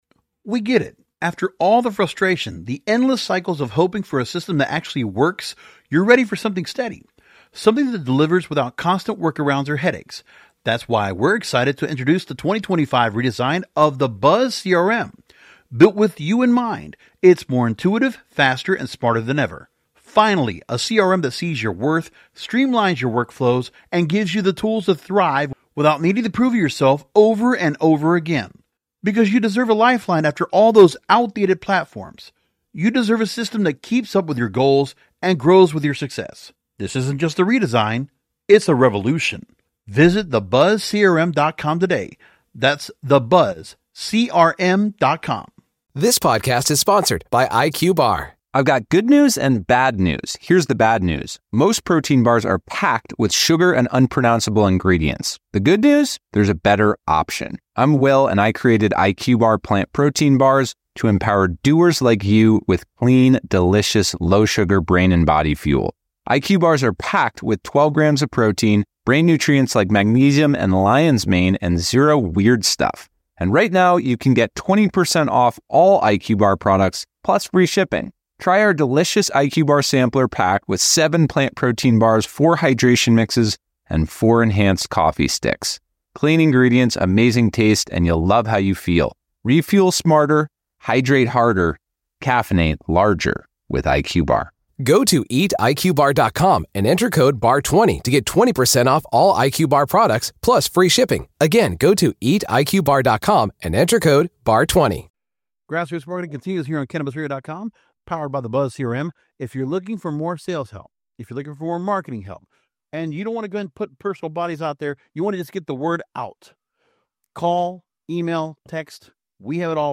The interview emphasizes the importance of community, mindful consumption, and creating elevated experiences around cannabis.